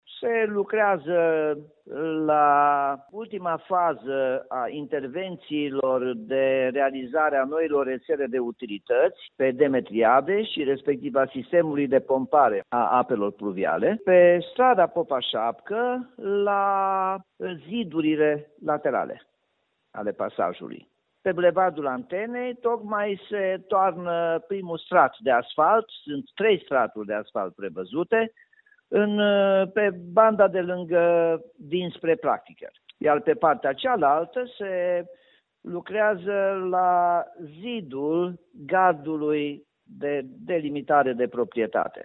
Nicolae Robu a efectuat astăzi o inspecție pe șantier. Potrivit edilului, în zonă vor fi lucrări și după această dată, dar se va putea circula pe toate cele patru benzi: